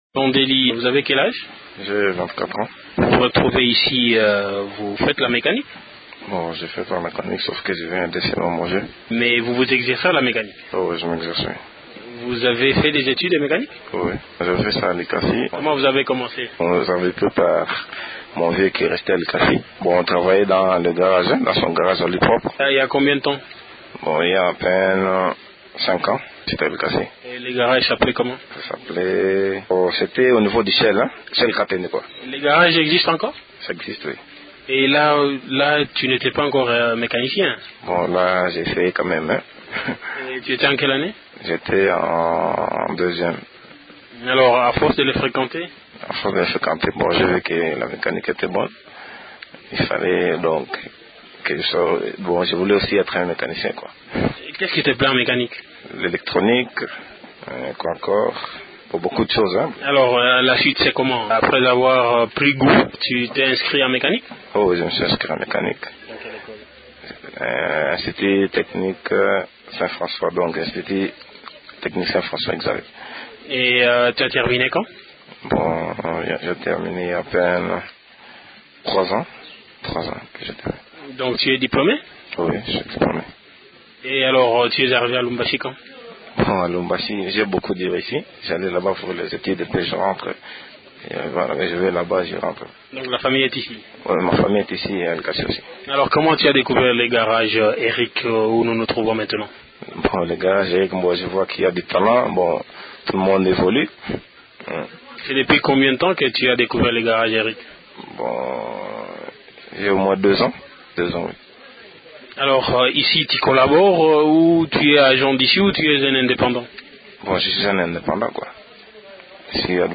Un entretien